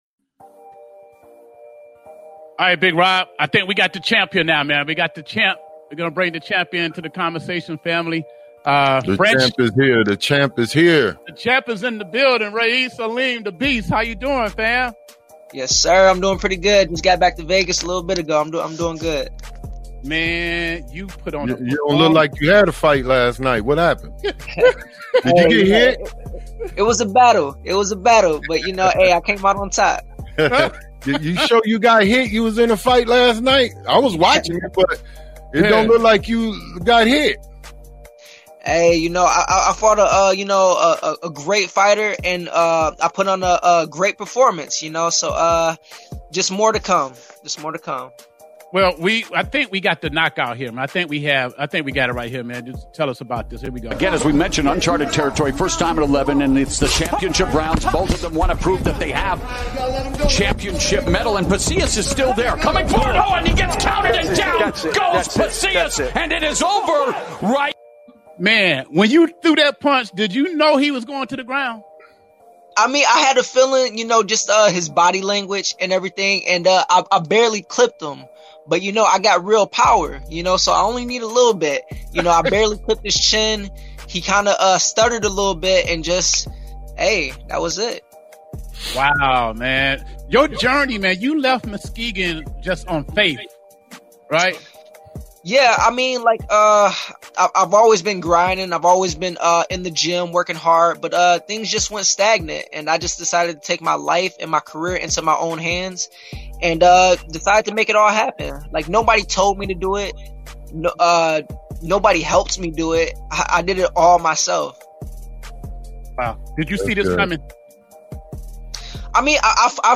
Interview with WBA World Boxing Champion Ra'eese Aleem